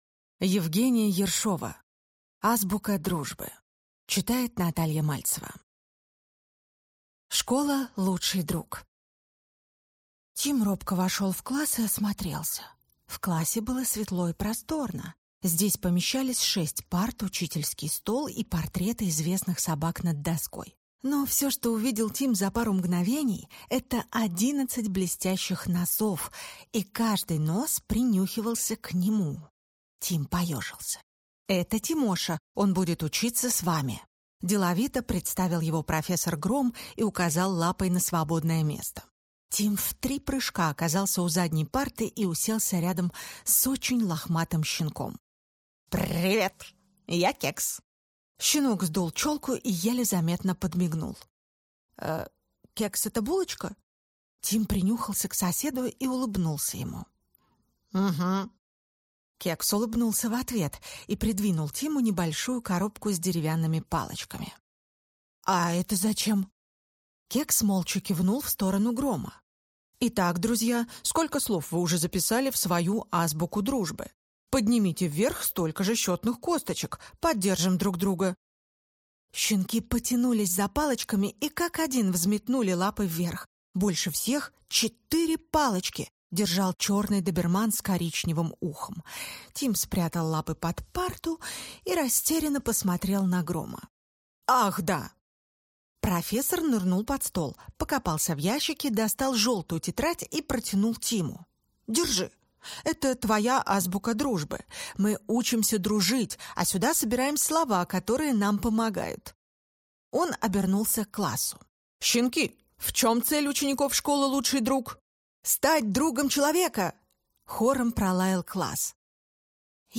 Аудиокнига Азбука дружбы | Библиотека аудиокниг